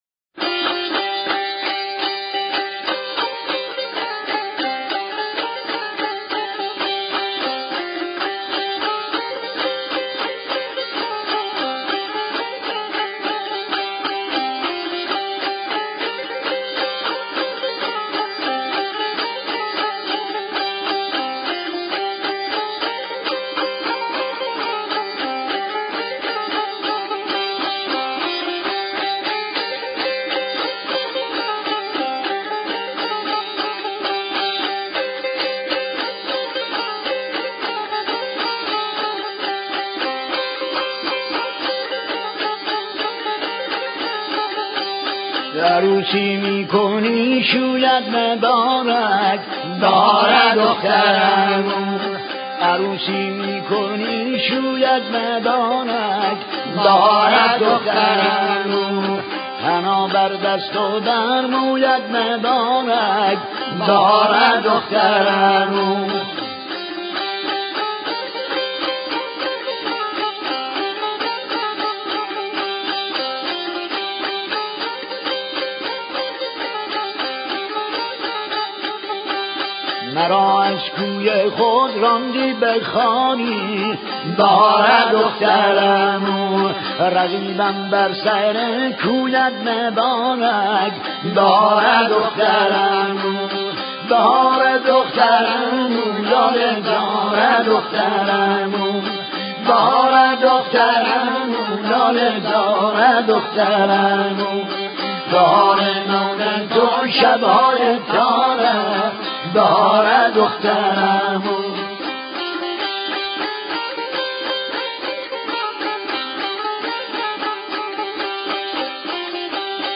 قطعه خراسانی "بهاره دختر عمو
قطعه خراسانی و عاشقانه